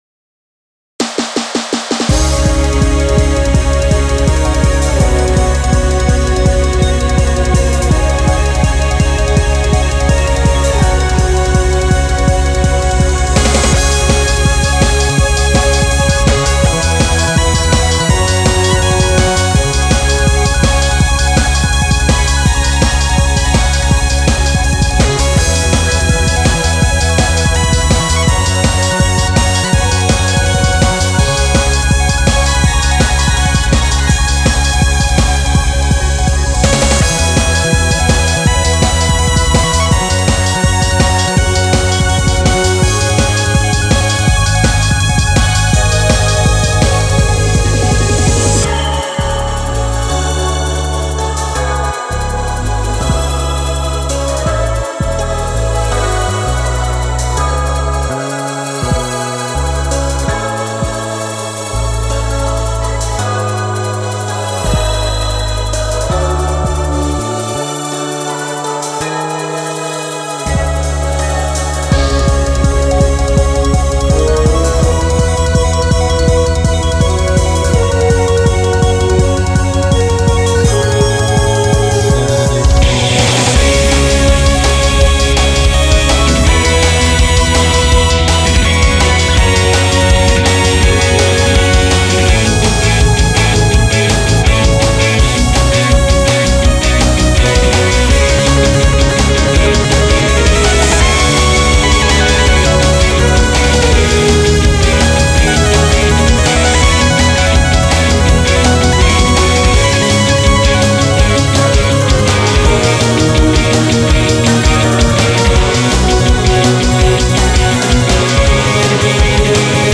シンセにHALion、コーラスにJacob Collier Audience Choirをほんのり加えてパワーアップ